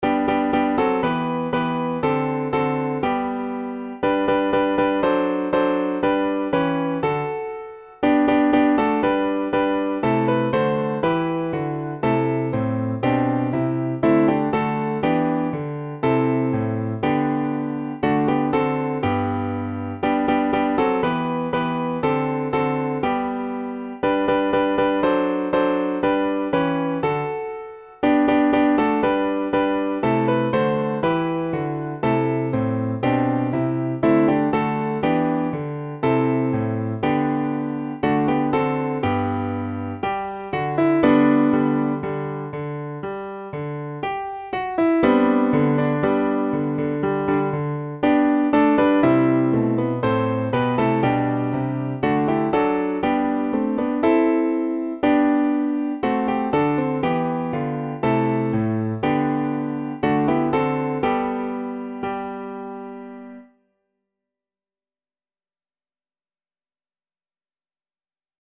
staccato、andante、legato
staccato、andante、legato 気高く、おろう Zambia http